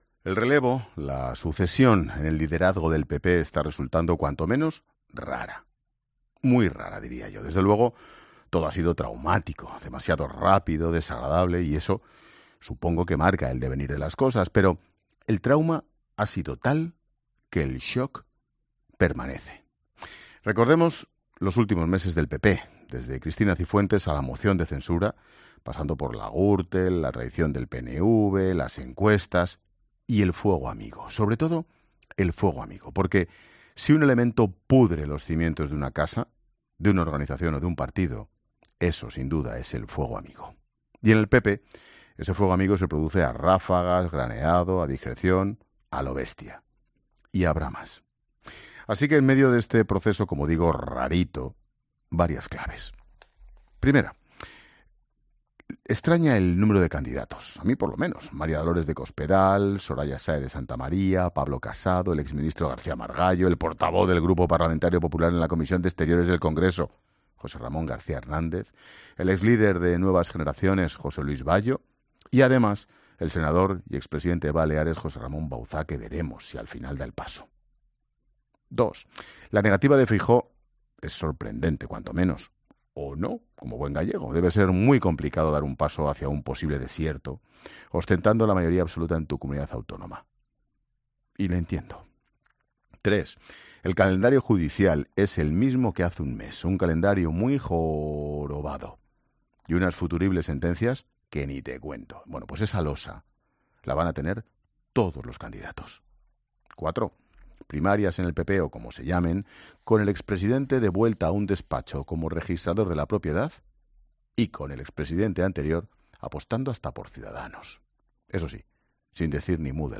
Monólogo de Expósito
El comentario de Ángel Expósito.